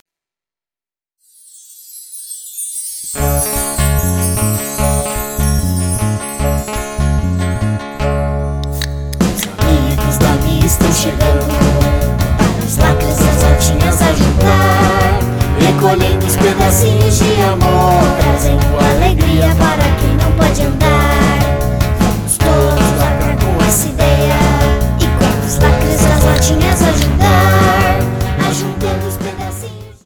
Piano, violão, carrilhão, bateria e baixo